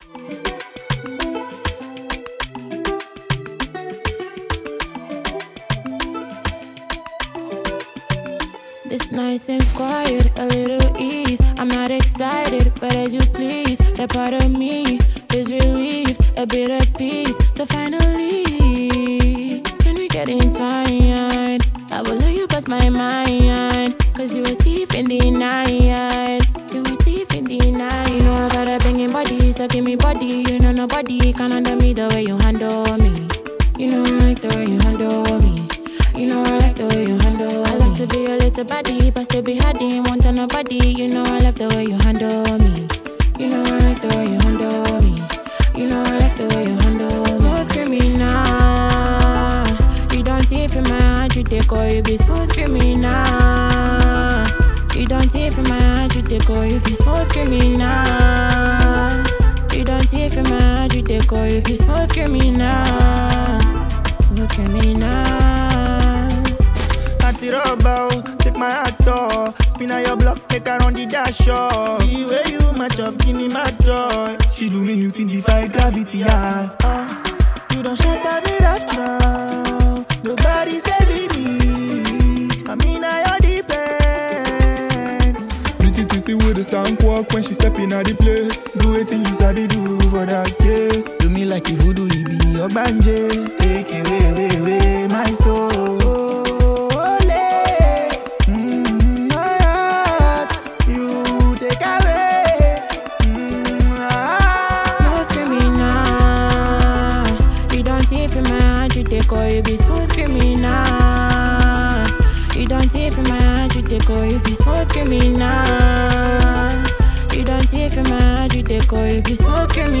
Liberian singer